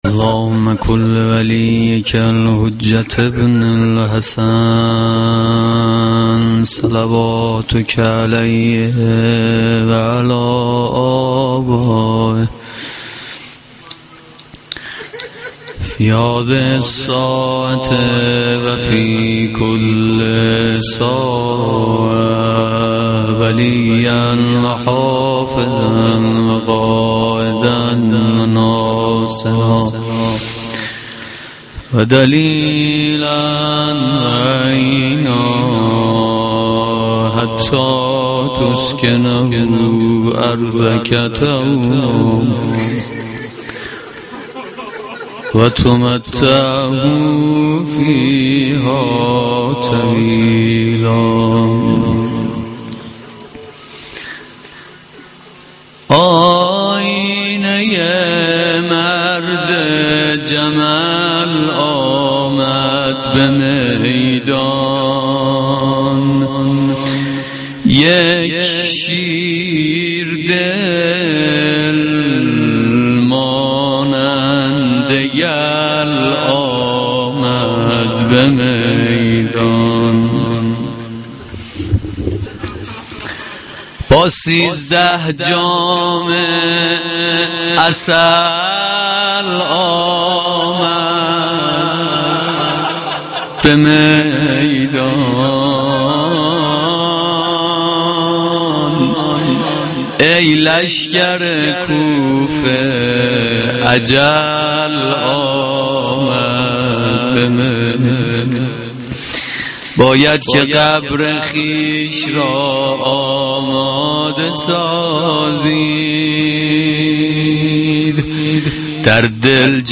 مداحی
Shab-6-Moharam-2.mp3